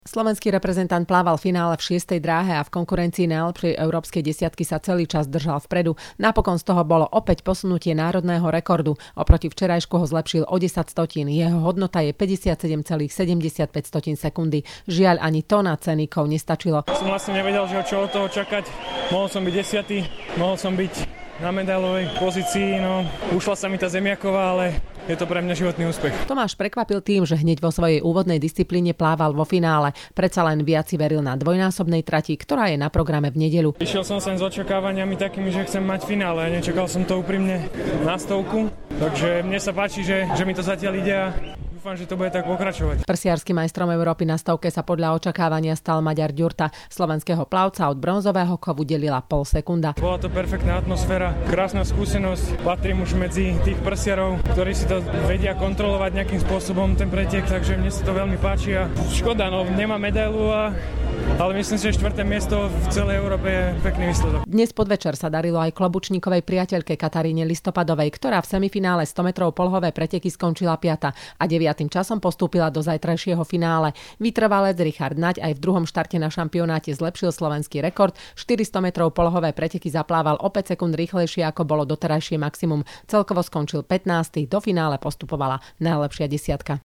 ME Dánsko - Rozhovor s Tomášom Klobučníkom po finále 100m prsia